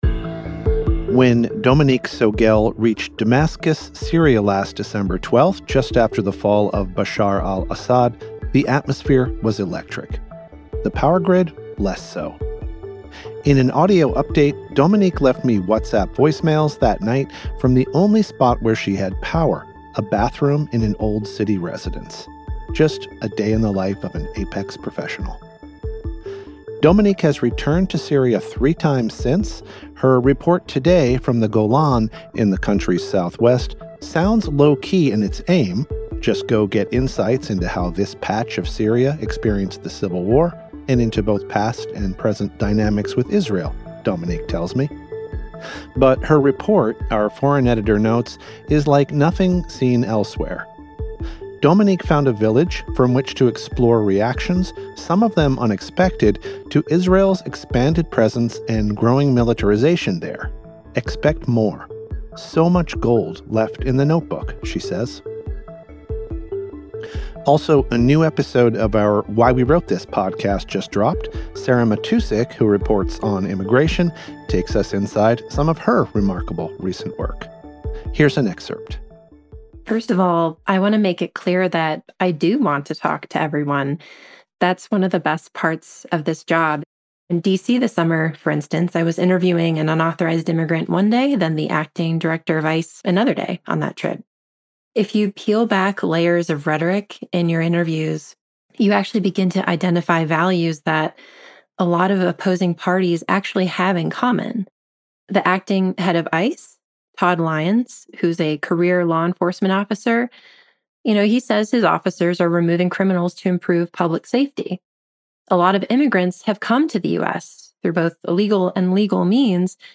The editors of The Christian Science Monitor take you beyond the headlines with the ideas driving progress in this 15-minute news briefing.